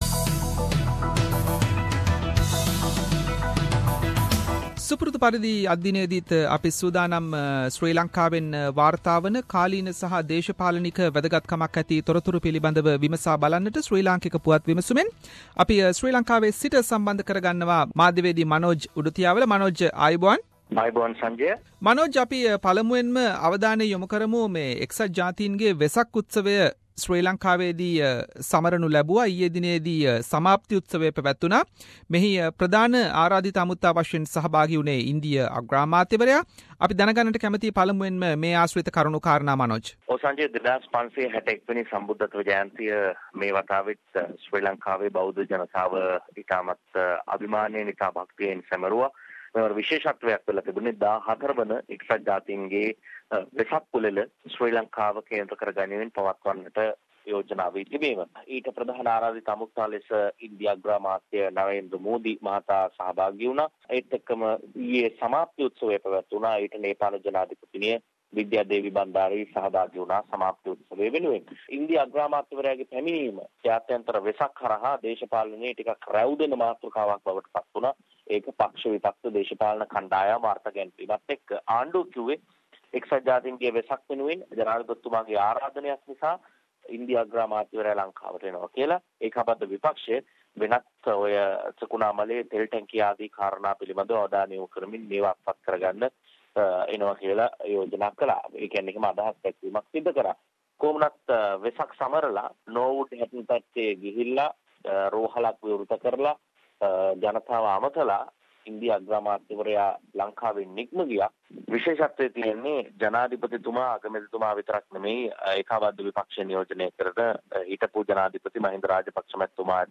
reports from Sri Lanka